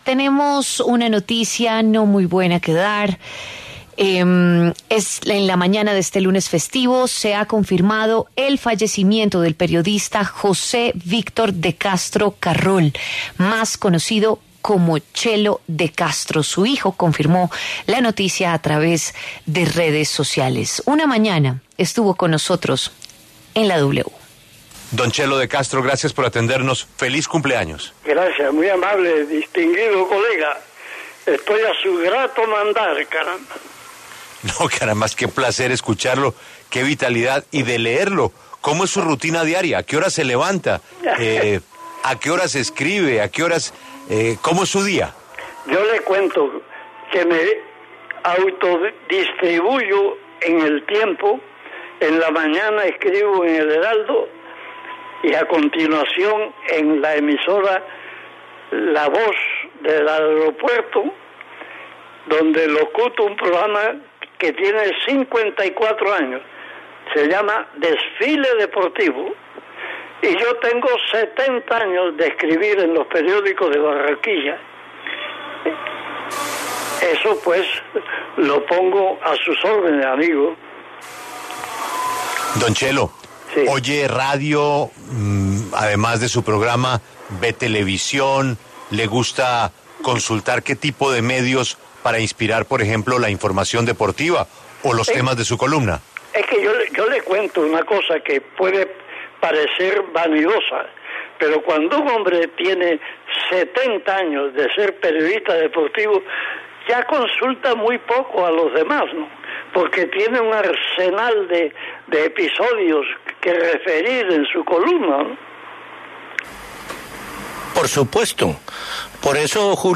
En W Fin de Semana rendimos un homenaje a Chelo de Castro y lo recordamos con una entrevista en la que conversó con Julio Sánchez Cristo, director de La W.